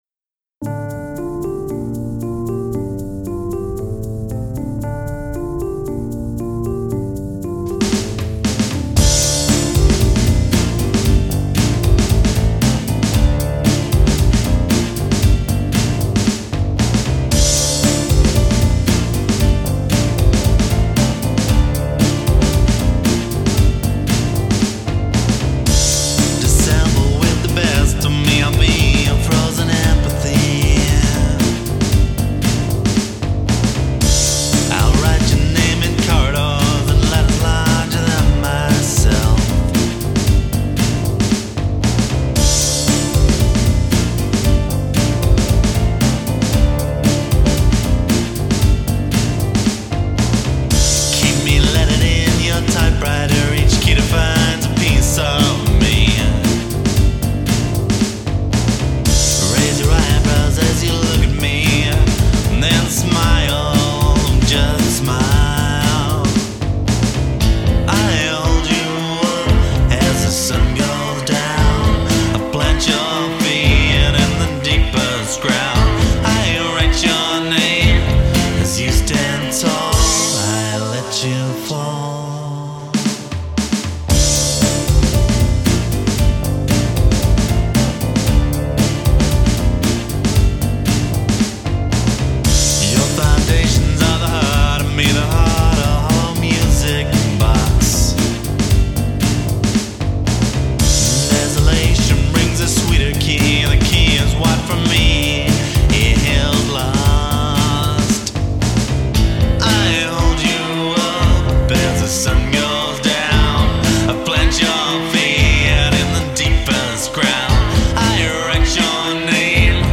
Songs - only rough demos, but they'll give you the idea:
Fully produced, performed and recorded